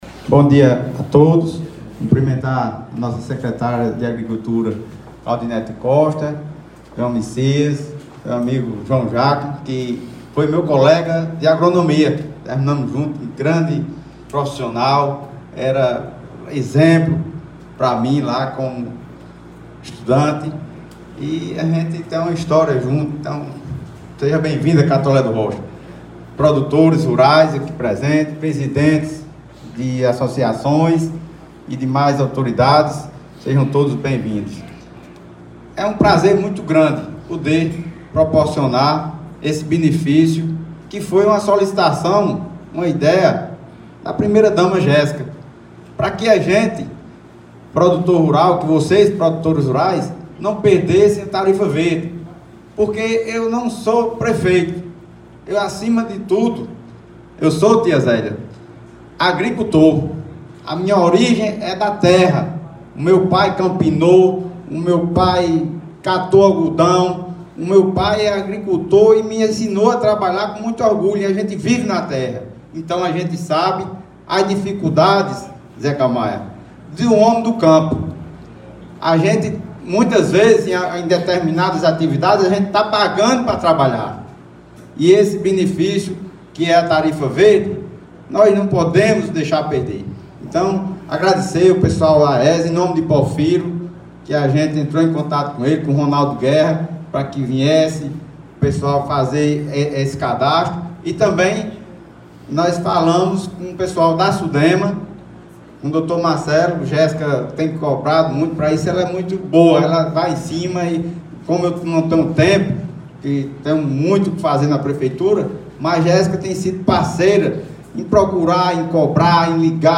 Durante a abertura dos trabalhos, o prefeito Laurinho Maia destacou a importância da ação para os agricultores.
Vamos as palavras do Prefeito Laurinho Maia: